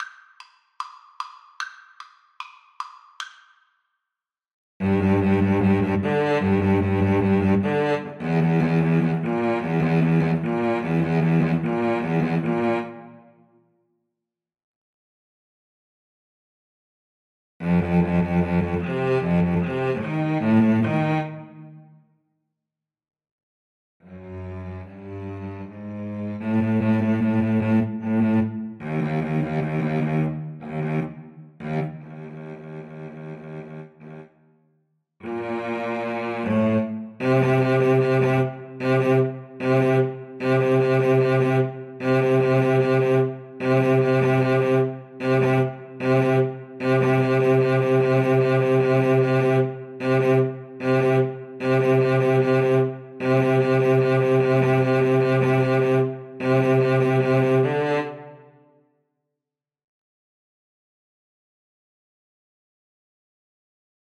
Free Sheet music for Cello Duet
Cello 1Cello 2
4/4 (View more 4/4 Music)
= 150 Allegro Moderato (View more music marked Allegro)
G major (Sounding Pitch) (View more G major Music for Cello Duet )
Classical (View more Classical Cello Duet Music)